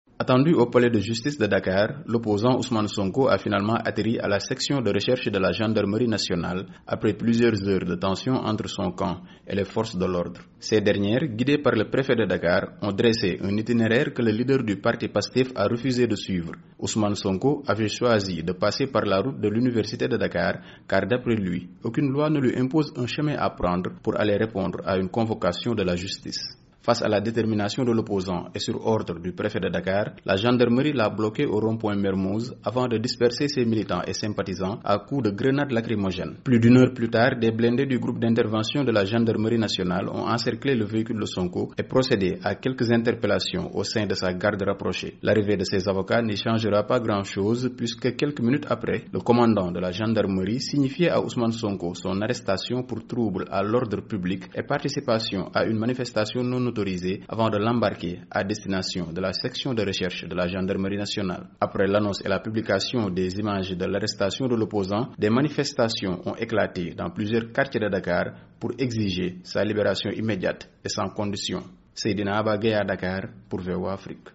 De notre correspondant à Dakar